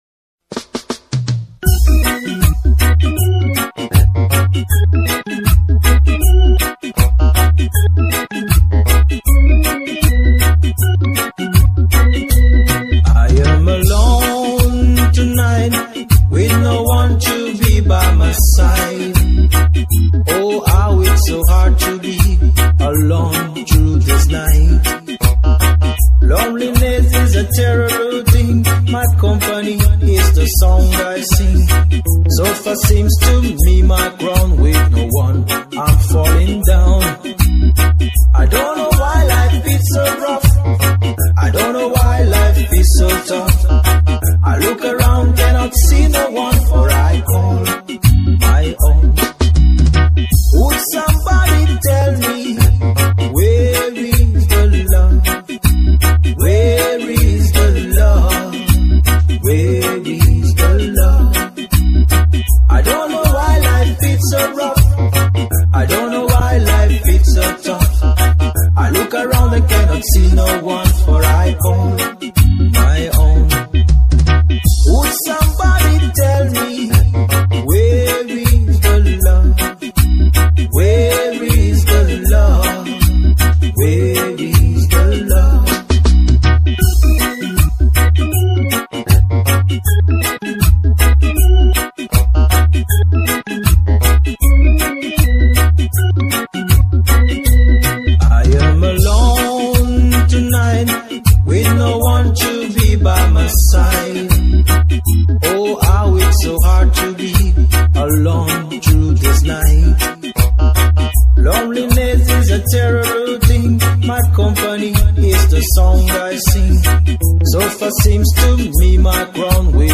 regae